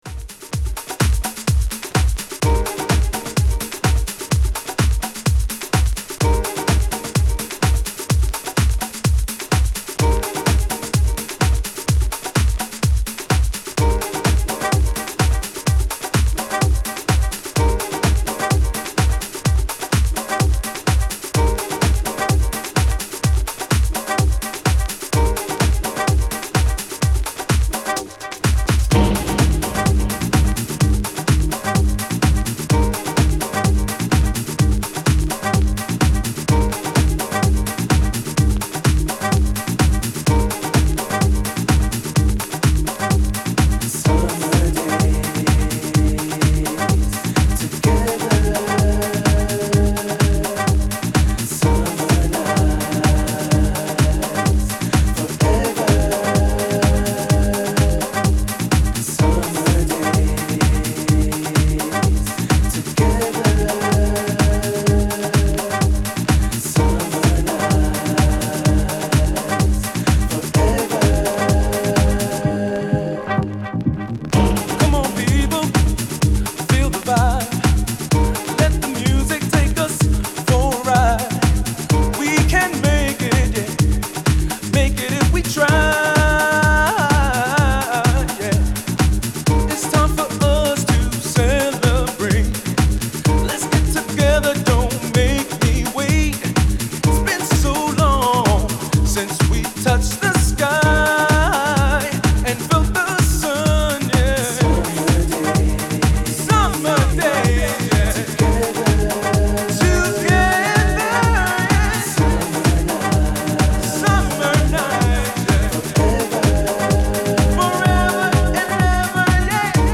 House / Techno
Vocal Mix